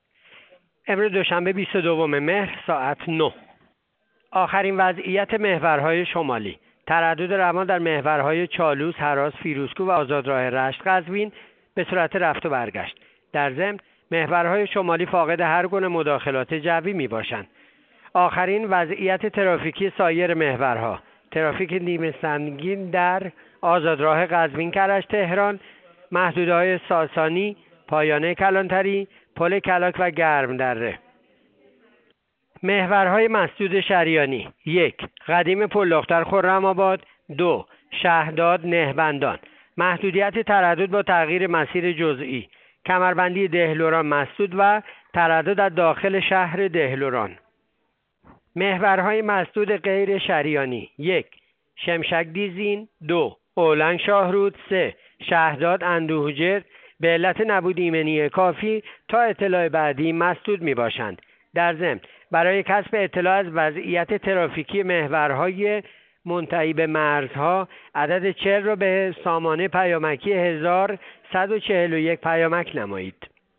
گزارش رادیو اینترنتی پایگاه خبری وزارت راه و شهرسازی از آخرین وضعیت ترافیکی جاده‌های کشور تا ساعت ۹ دوشنبه ۲۲ مهر/ترافیک نیمه سنگین در محور پایانه مرزی مهران-ایلام-حمیل